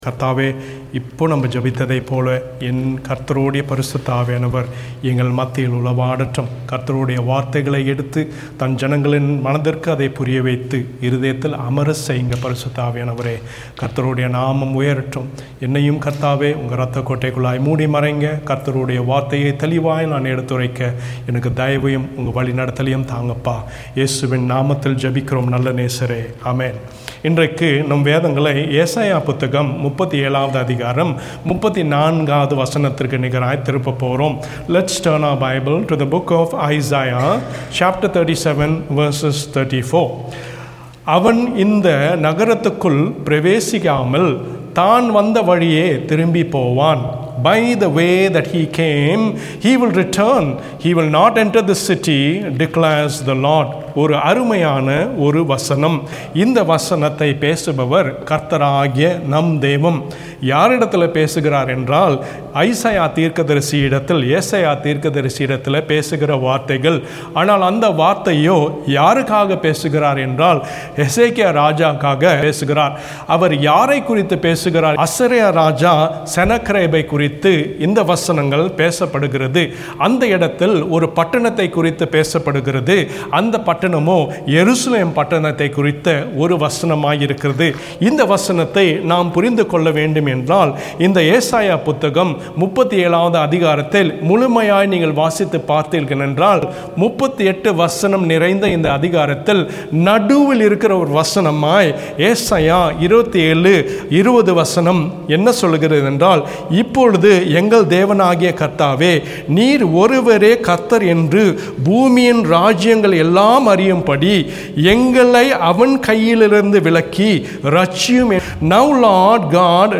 He alone is Lord - Hope Tamil Church Southampton